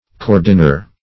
cordiner - definition of cordiner - synonyms, pronunciation, spelling from Free Dictionary Search Result for " cordiner" : The Collaborative International Dictionary of English v.0.48: Cordiner \Cor"di*ner\ (k[^o]r"d[i^]*n[~e]r), n. A cordwainer.